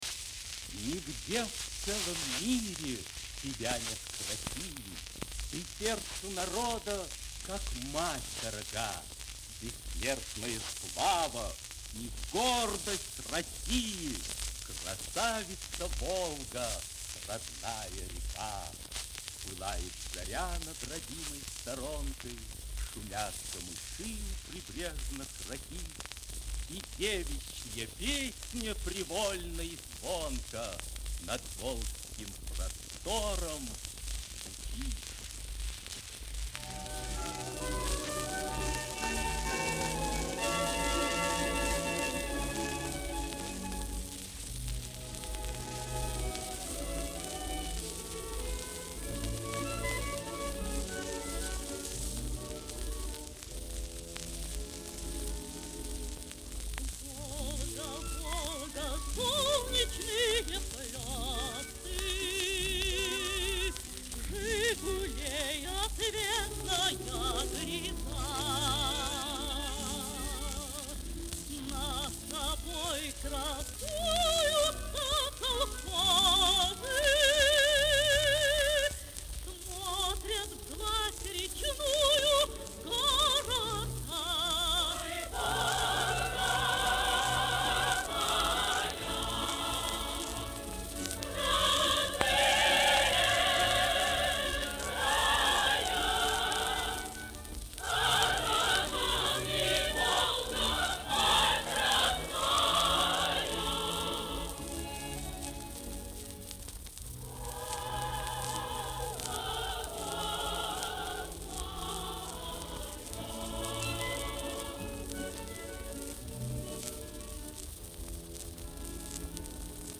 Эпическое произведение